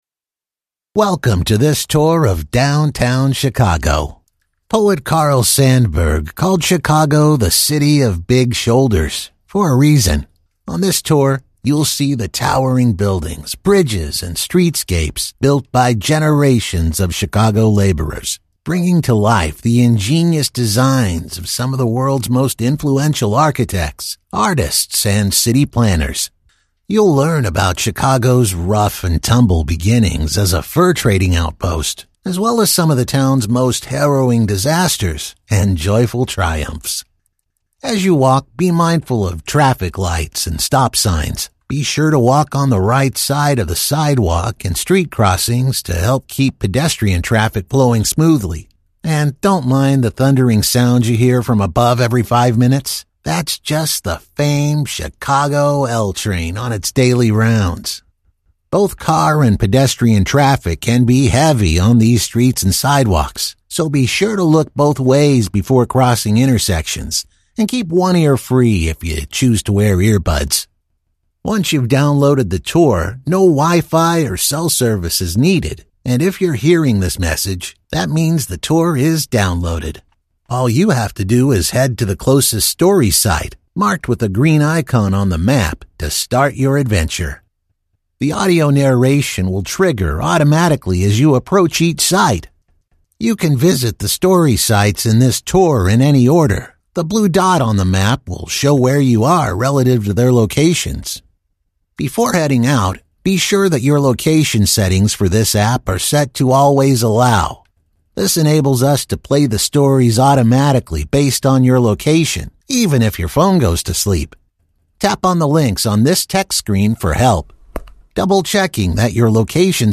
Male
English (North American)
Tour Guide
Words that describe my voice are Real, Fun, Friendly.